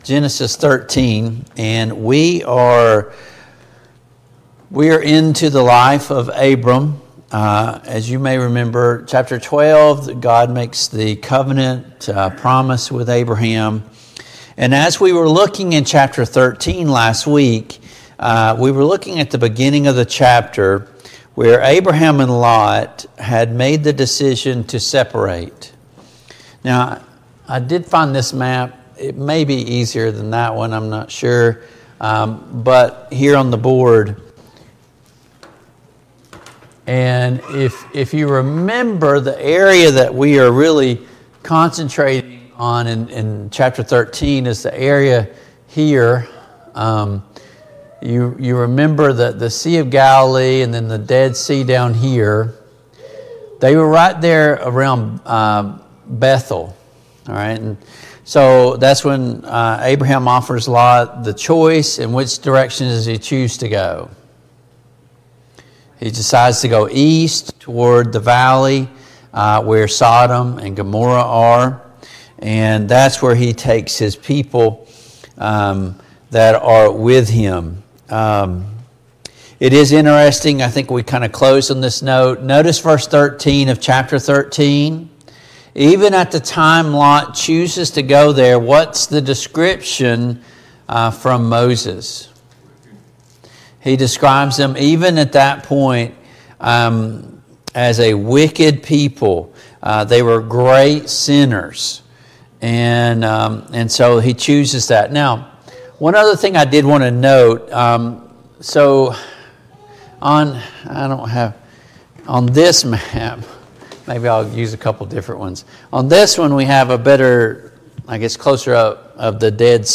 Service Type: Family Bible Hour Topics: Abraham and Sarah , God's covenant with Abraham , Melchizedek